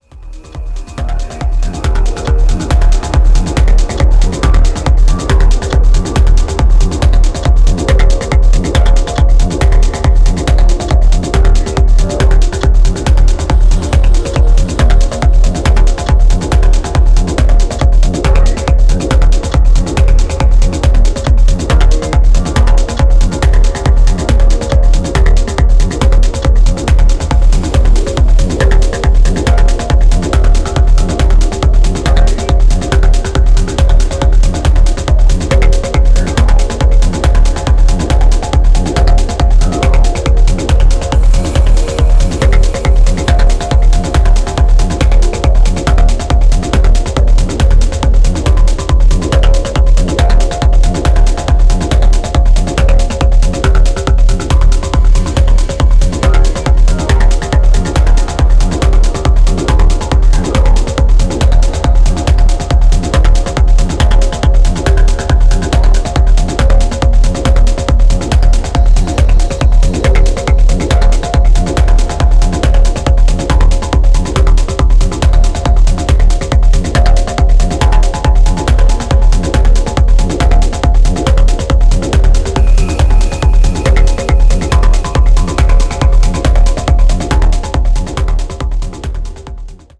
フロア完全対応なディープ・テクノ推薦盤。